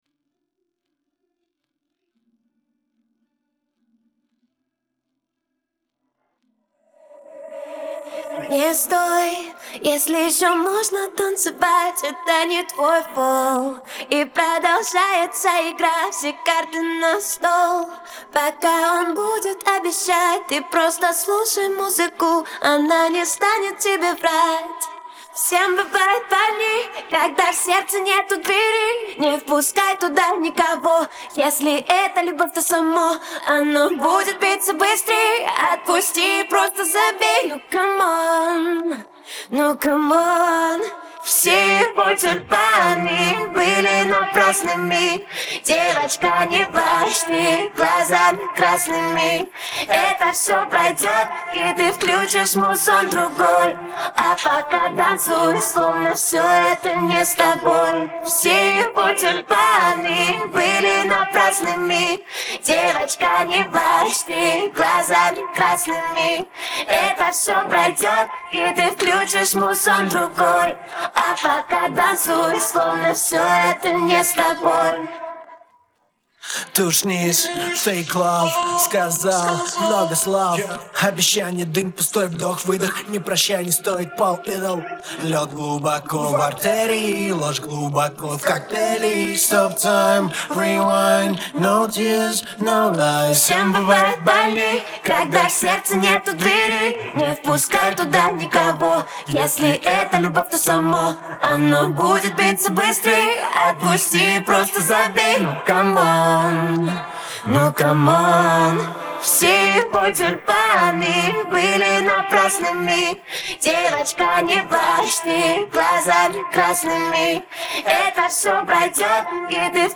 Parte vocal